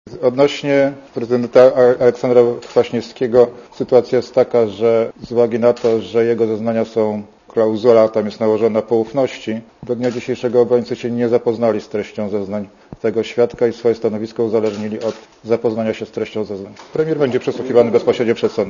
Mówi sędzia Wojciech Małek (76Kb)